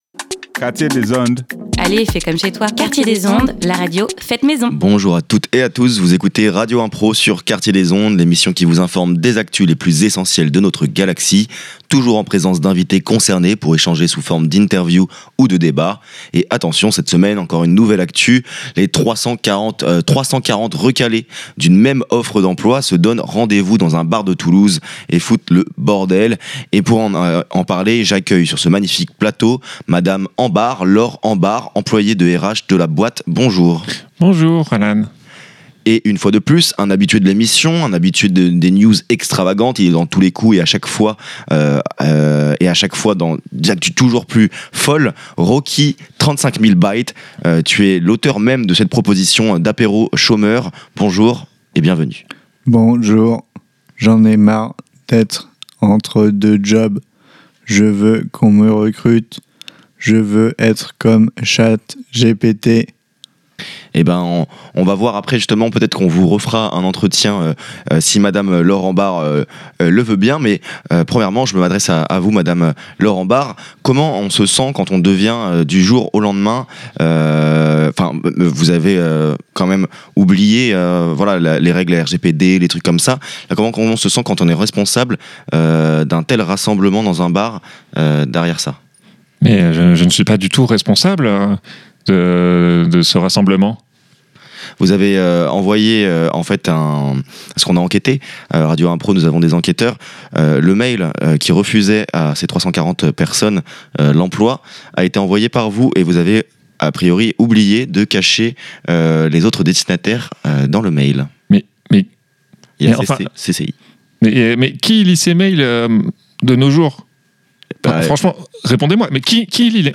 Bienvenue sur radio impro !